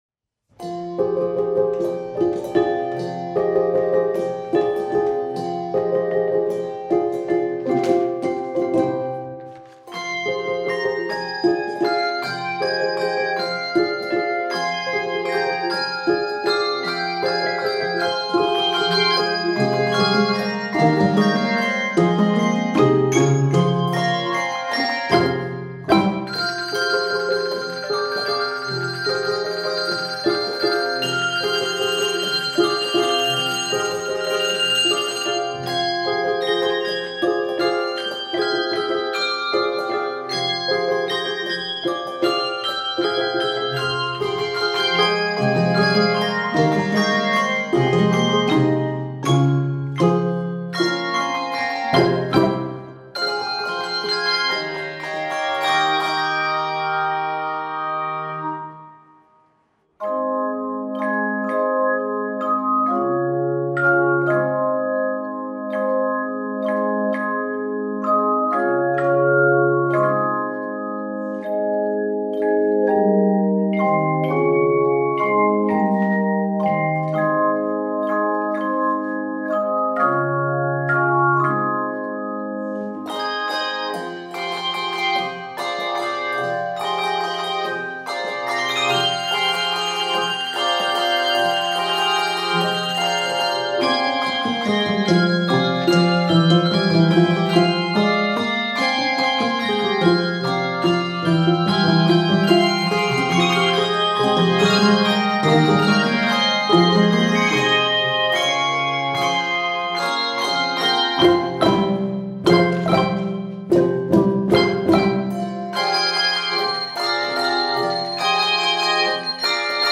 Key of f minor.